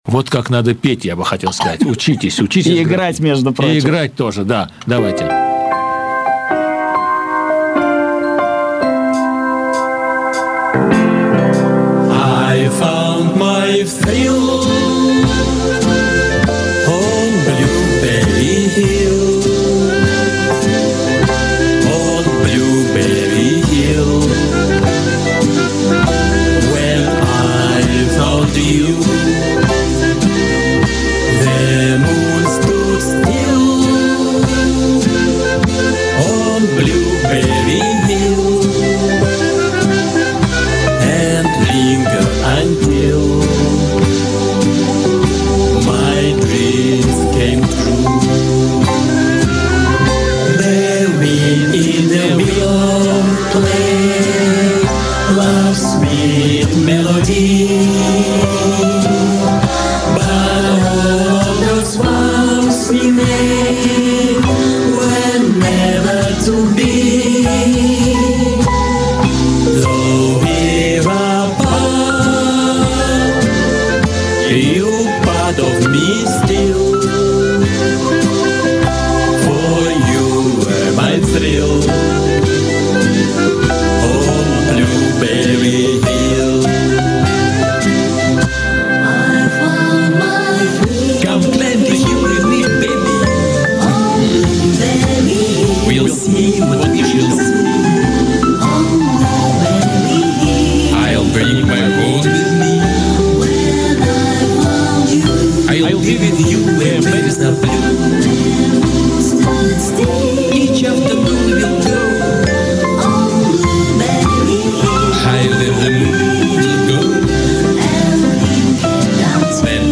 Играет и поёт новый старый Президент!